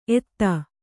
♪ eti